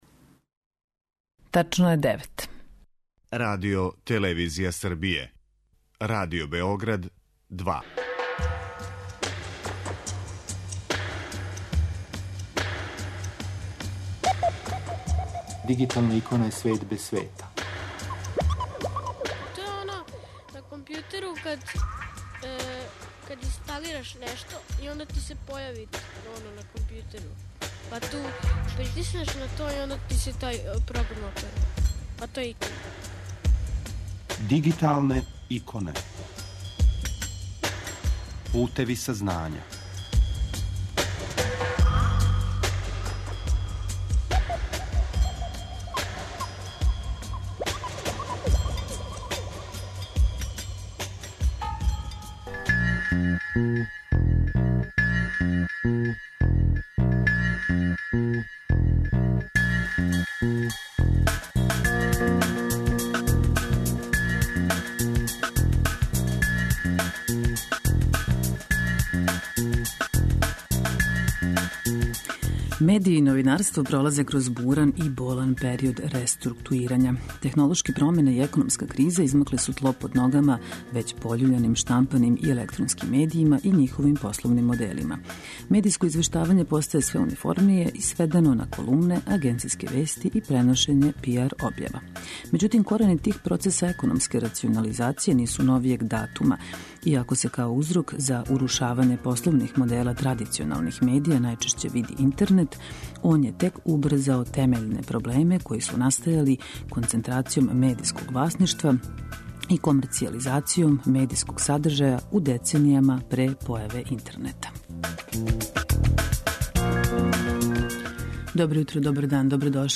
У данашњој емисији слушамо разговоре и белешке са међународне конференције 'Медији против демокрације?' која је од 24. до 26. априла одржана у Загребу, у Гете институту, у организацији Мултимедијалног института МАМА, Курзива и Културтрегера ('Booksa').
У данашној емисији најавићемо и коментарисати актуелне догађаје из области информационо-комуникационих технологија и наставити емитовање интервјуа снимљених у оквиру конференције Е-трговина која је недавно одржана на Палићу.